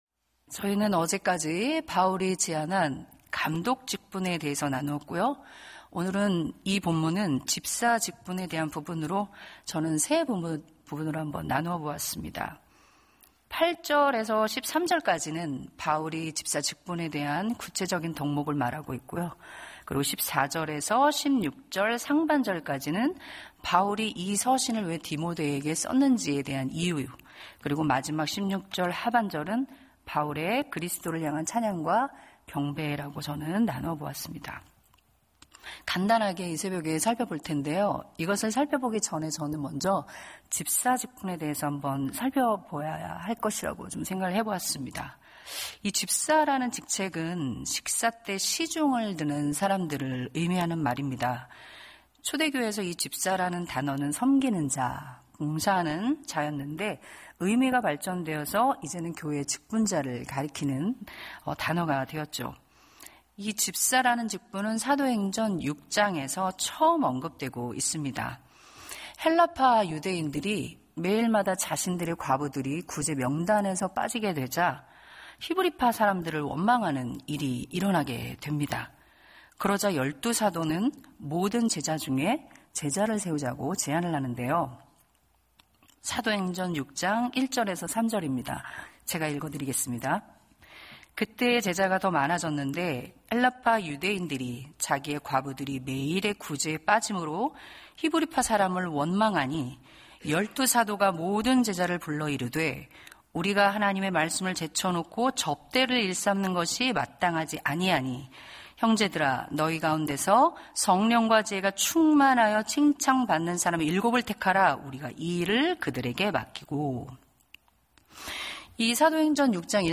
새벽예배